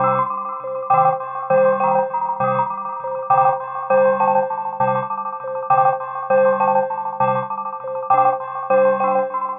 tx_synth_100_bells.wav